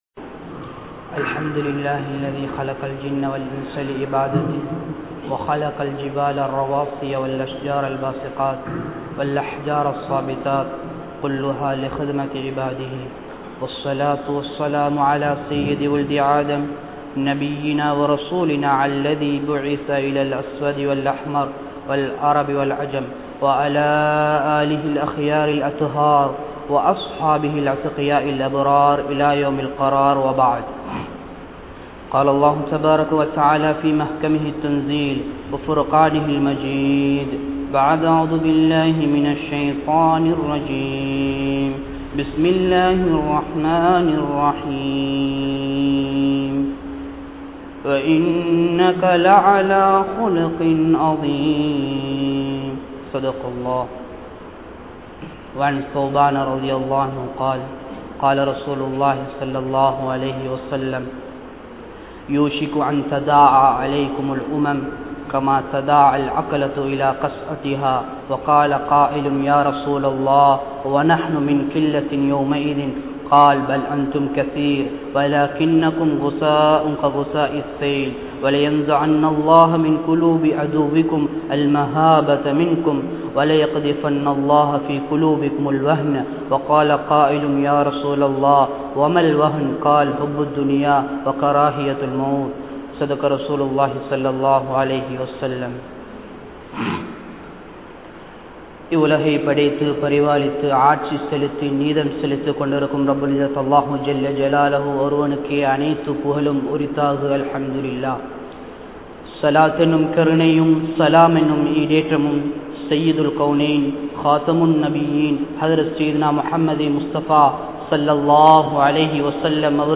Current Situation In Sri Lanka | Audio Bayans | All Ceylon Muslim Youth Community | Addalaichenai
Gorakana Jumuah Masjith